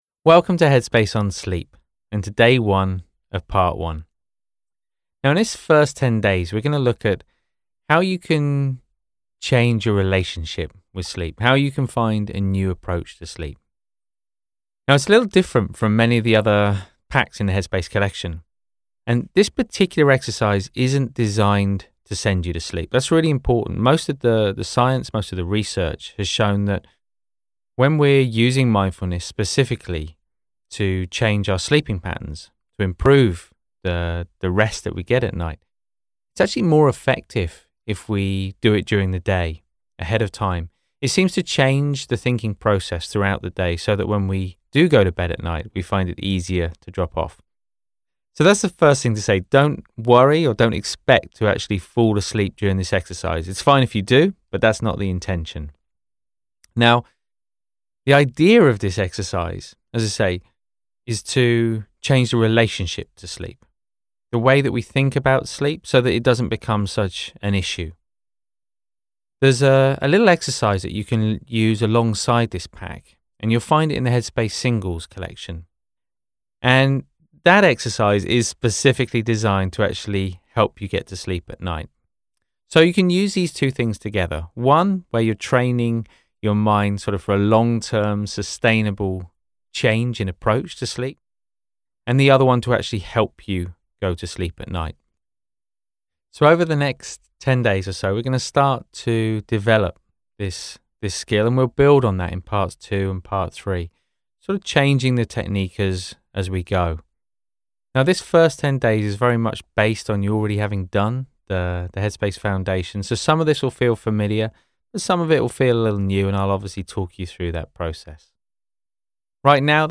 Meditation_for_Sleep.mp3